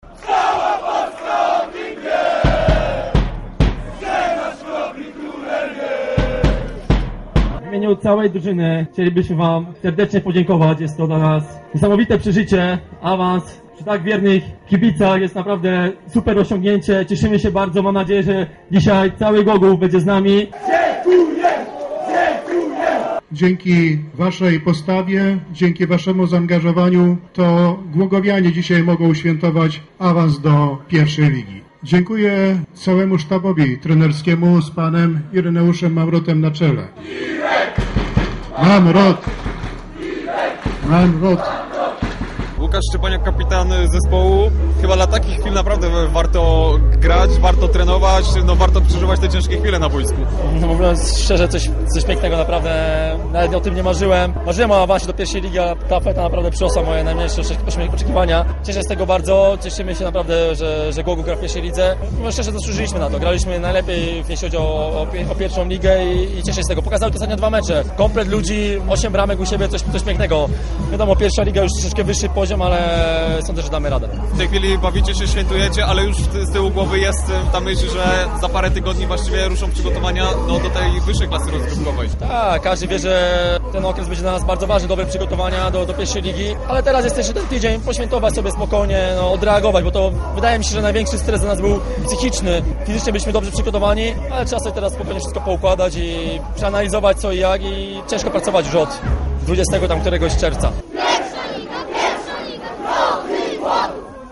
W tym miejscu kibice i cały zespół Chrobrego świętował awans do pierwszej ligi. Feta zgromadziła w rynku setki głogowian.
Potem były przyśpiewki, podziękowania i pamiątkowe zdjęcia.
Na koniec były race i fajerwerki.